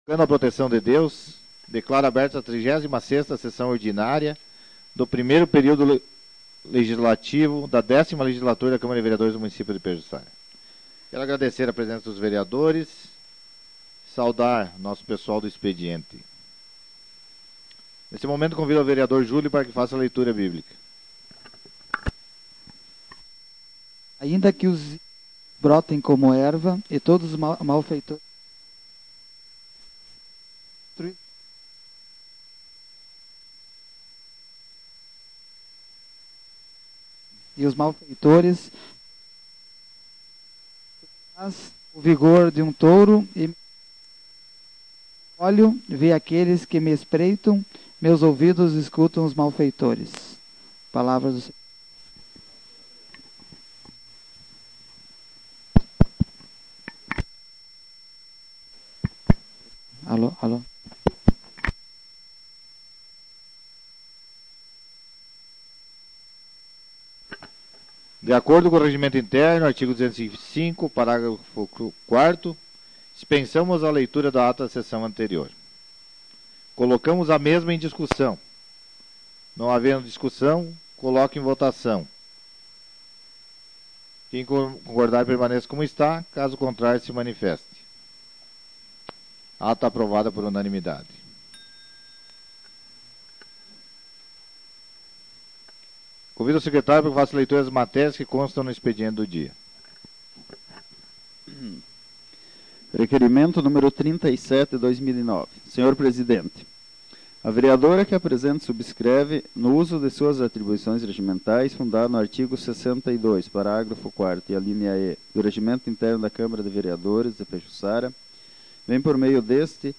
Sessão Ordinária de 2010